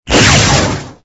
lightning_3.ogg